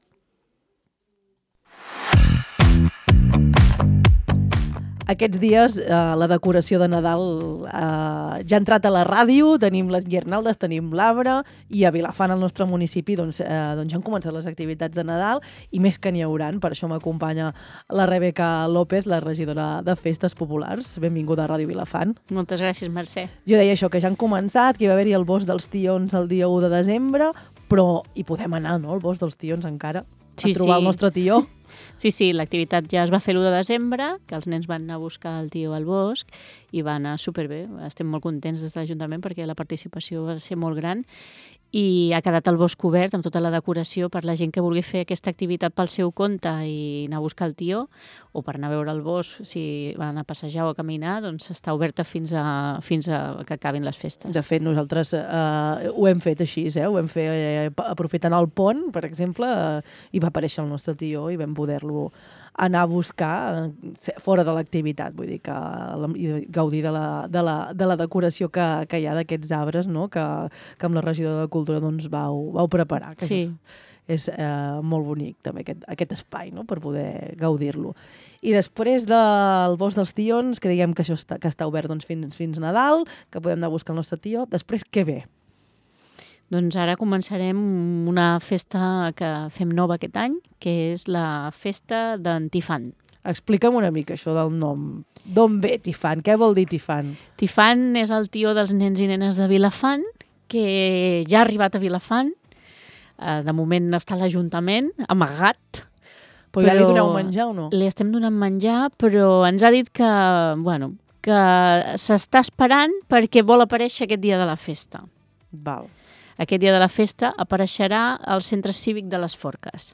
La regidora de festes de Vilafant Rebeca López passa per les veus del matí per parlar-nos de totes les activitats de Nadal que s’han preparat al municipi per aquests propers dies. Ens ha destacat una nova activitat, el Tifant, el tió popular del proper dia 21 de desembre. Escolta aquí l’entrevista: